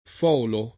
Folo [ f 0 lo ]